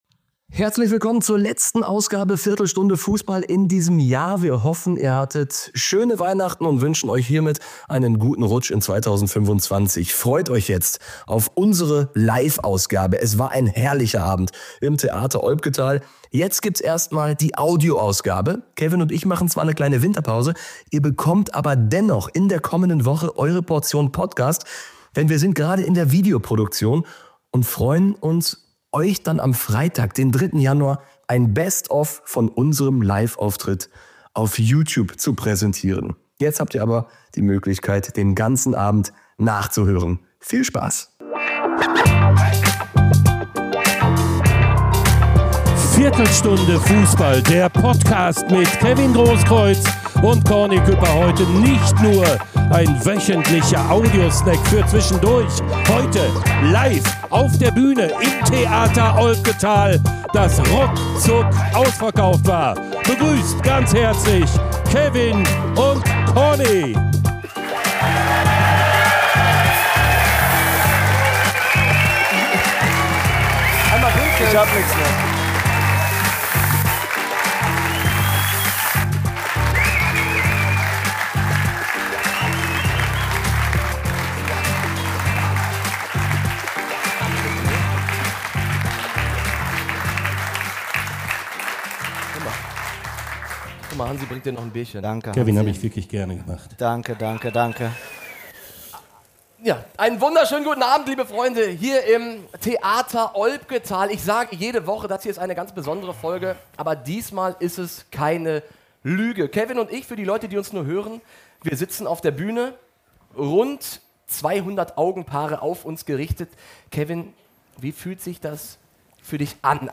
Zwei Kumpels, die diesen Sport aus unterschiedlichen Perspektiven kennengelernt haben und erleben.